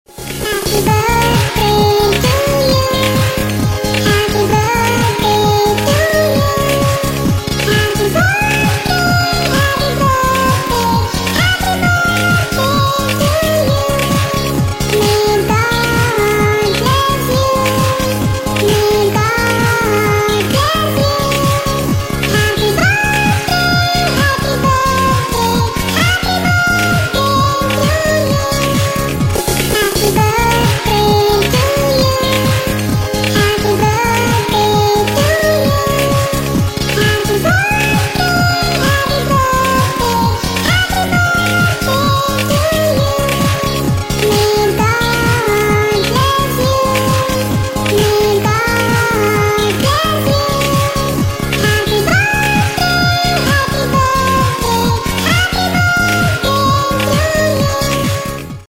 Поздравление бурундуков с днем рождения для детей